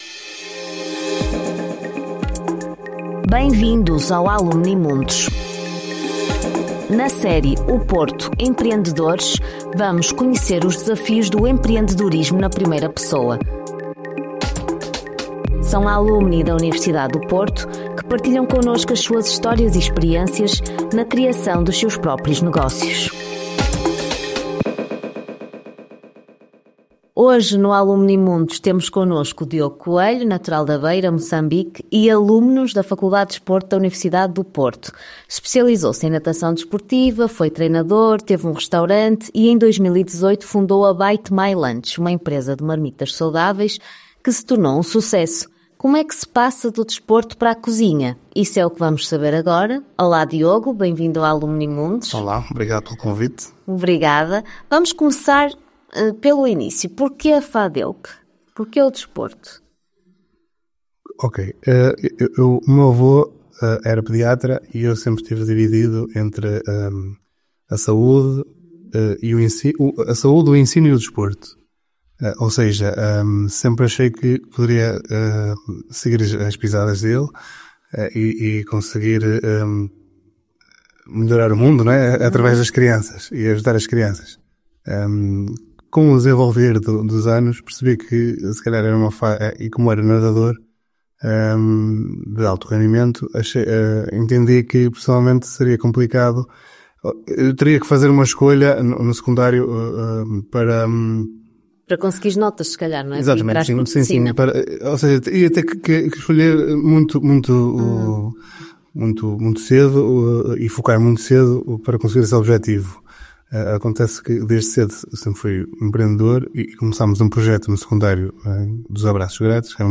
Bite my Lunch: a empresa de marmitas saudáveis que quer ser considerada um lifestyle Nesta entrevista